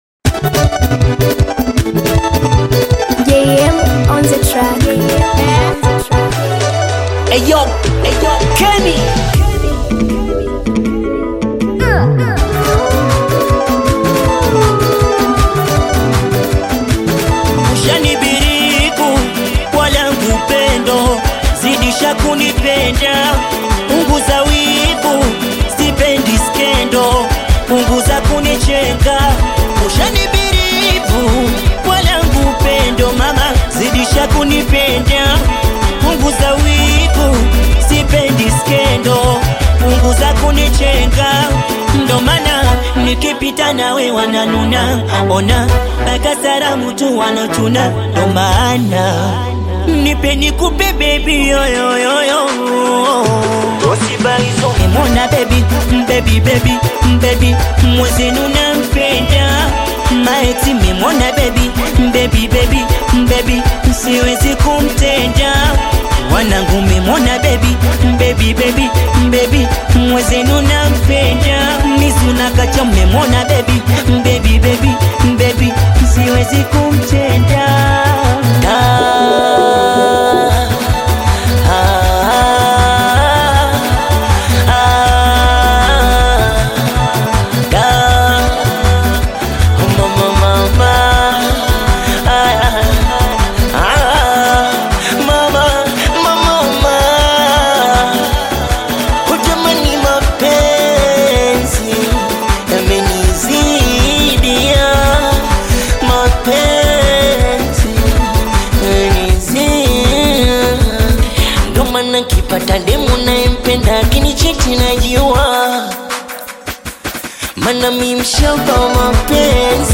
Bongo flava singeli
upbeat singeli song